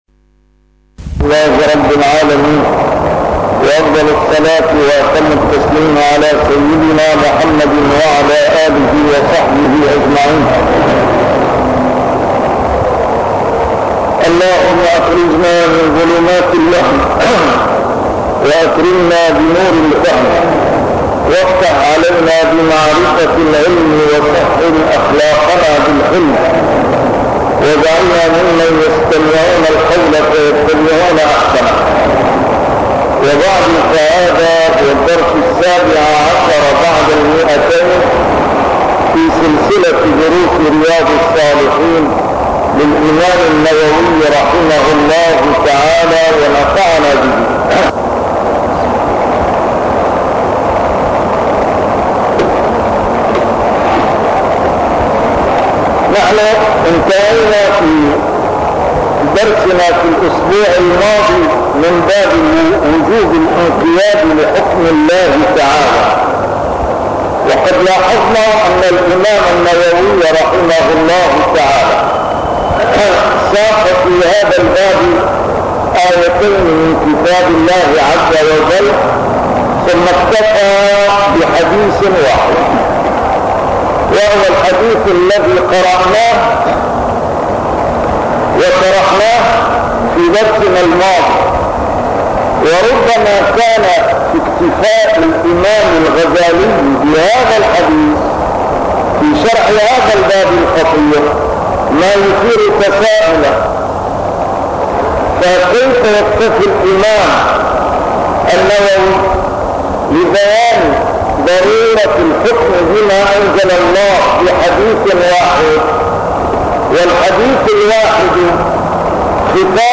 A MARTYR SCHOLAR: IMAM MUHAMMAD SAEED RAMADAN AL-BOUTI - الدروس العلمية - شرح كتاب رياض الصالحين - 217- شرح رياض الصالحين: الانقياد لحكم الله + النهي عن البدع